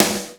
RS SNR 1  -L.wav